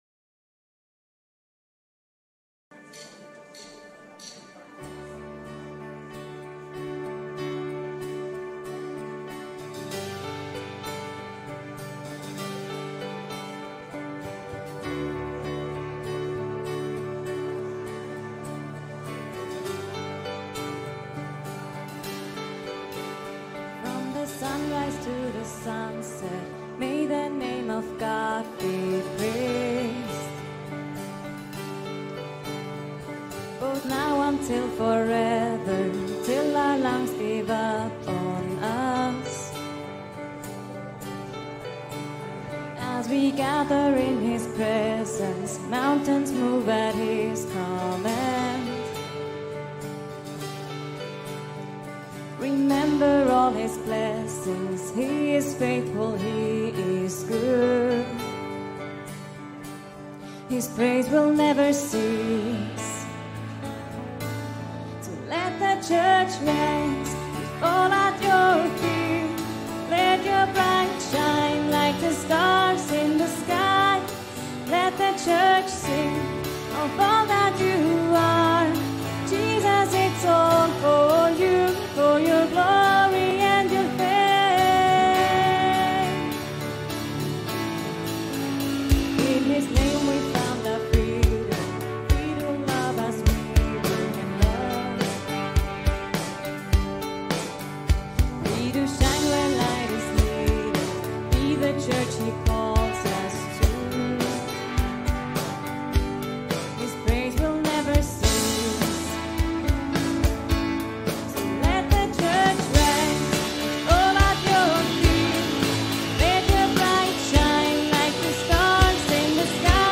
Abschiedsgottesdienst